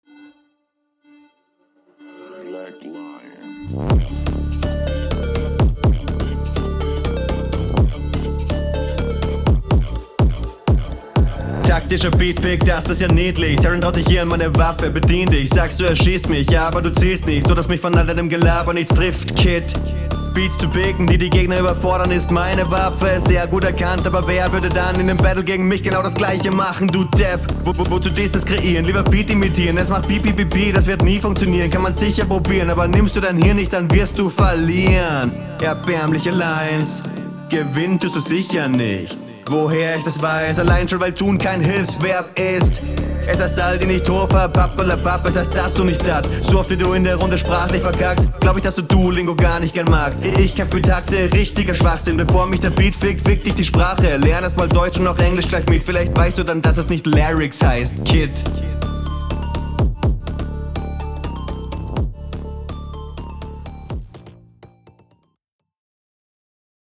Stimme etwas laut im Verhältnis zum Beat.